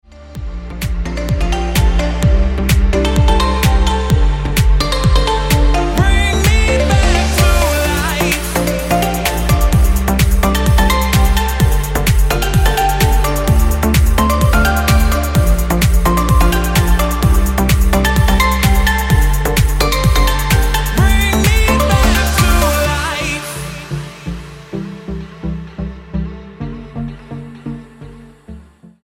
STYLE: Dance/Electronic
soulful vocals to the chilled out vibes